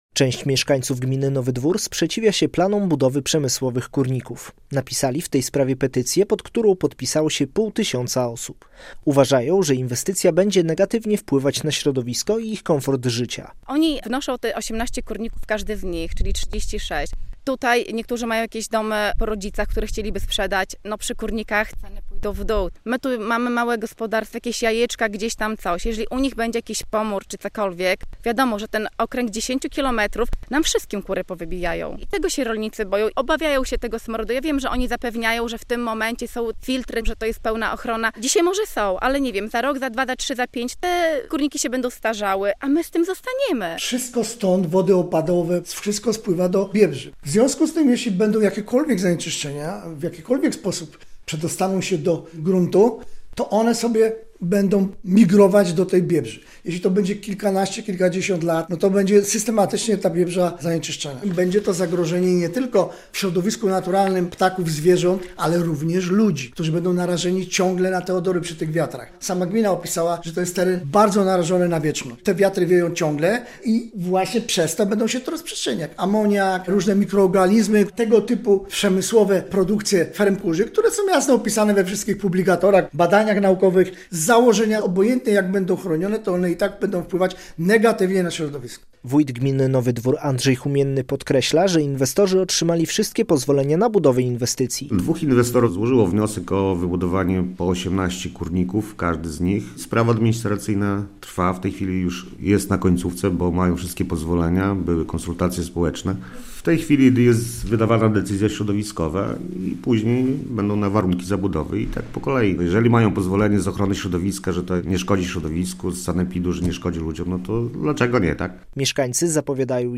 Mieszkańcy sprzeciwiają się planom budowy ponad 30 kurników w gminie Nowy Dwór - relacja
- mówi wójt gminy Nowy Dwór Andrzej Humienny.